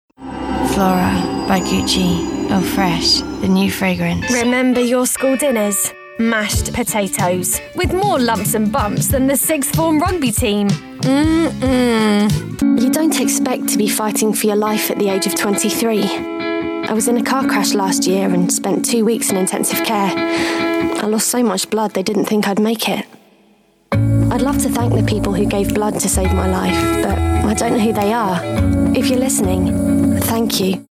Commercial 1